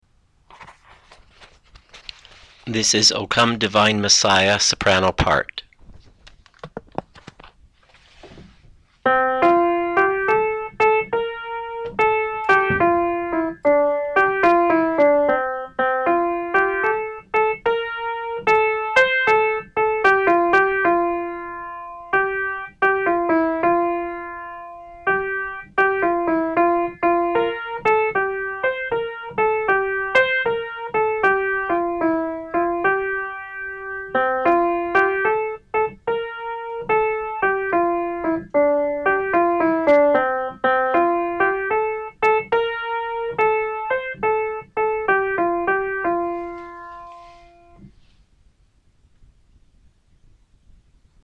O Come Divine Messiah - Soprano 2012-10-20 Choir